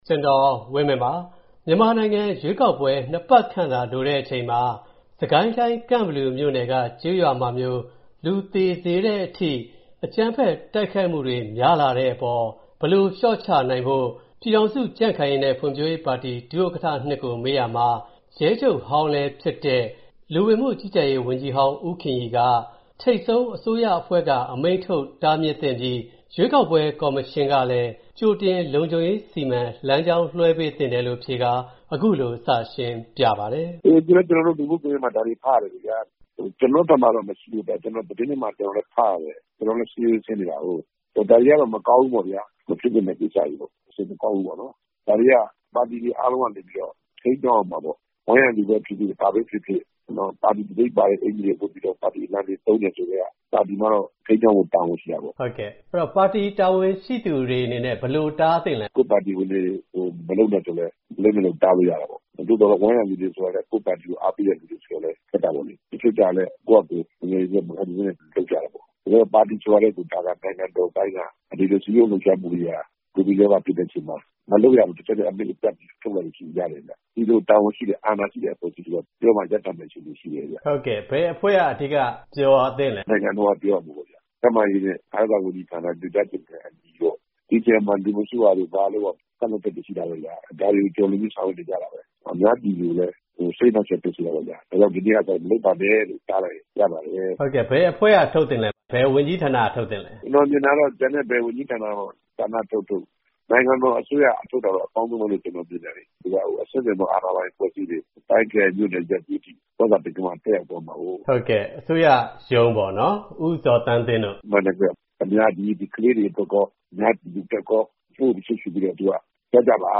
ရွေးကောက်ပွဲအကြို အကြမ်းဖက်မှုတွေအရေး USDP ဒုတိယဥက္ကဋ္ဌ-၂ ဦးခင်ရီနဲ့ သီးသန့်မေးမြန်းခန်း